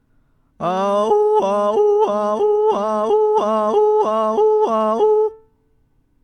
３. 素早く地声と裏声を行き来する
音量注意！